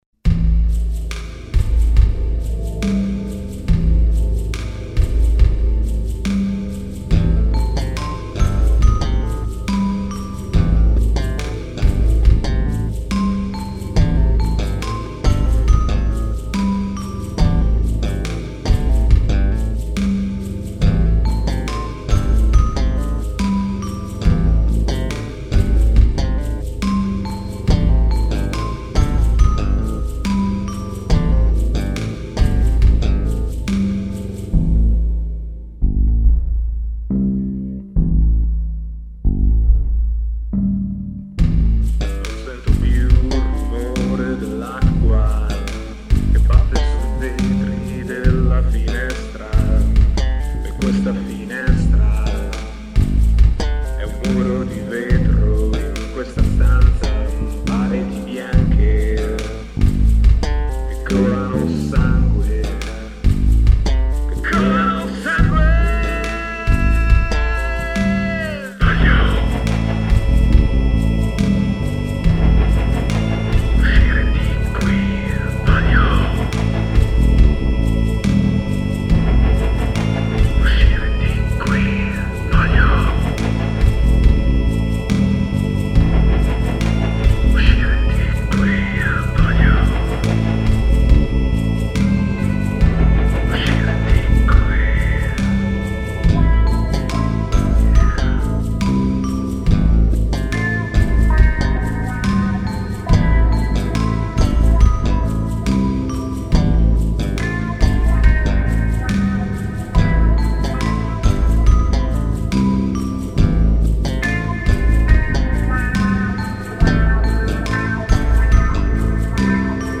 Voci e Programming
Basso
Chitarra